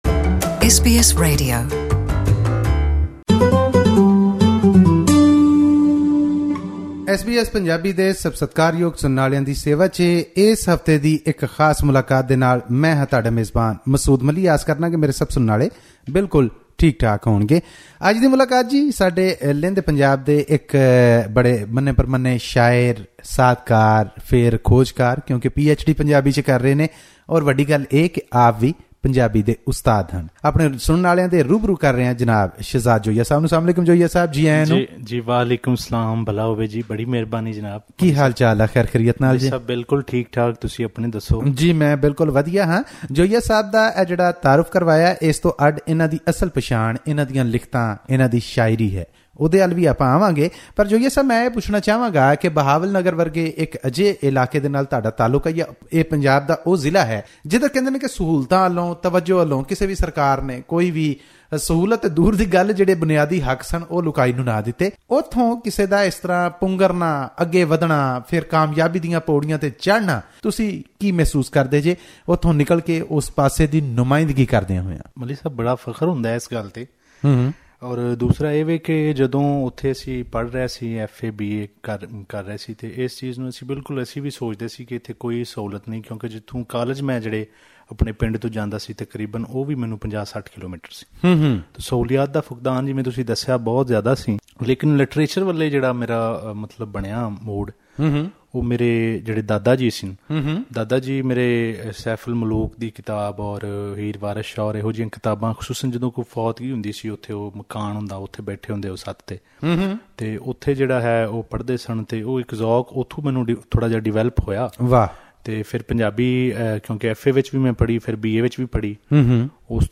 To listen to this interview in Punjabi, click on the player at the top of the page.